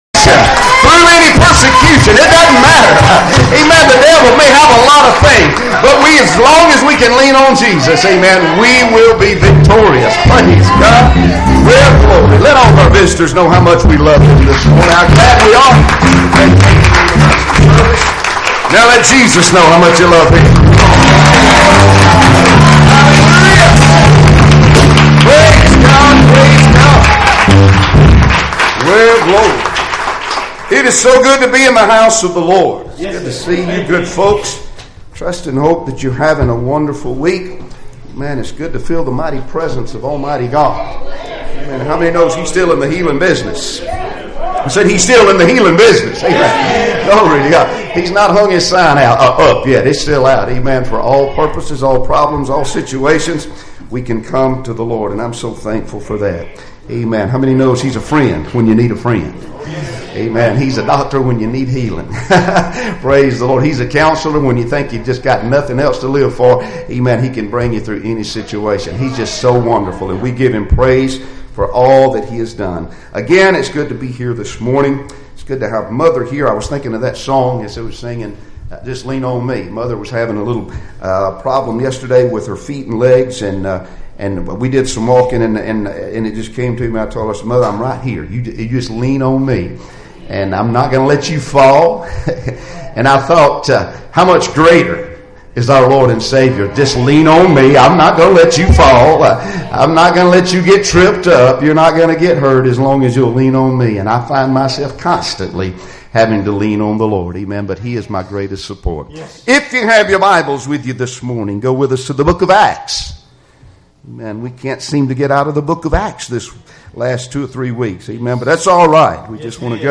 Passage: Acts 9:1-9 Service Type: Sunday Morning Services Topics